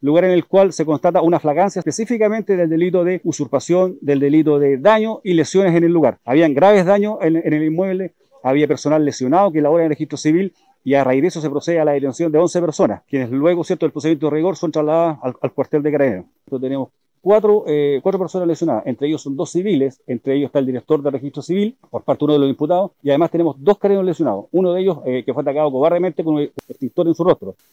Tras ser alertado personal de Carabineros, se activó un operativo que en pocos minutos llegó al acceso al edificio y detuvo en flagrancia a quienes protagonizaban la ocupación, detalló el coronel y prefecto en Cautín, César Bobadilla.